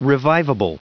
Prononciation du mot revivable en anglais (fichier audio)
Prononciation du mot : revivable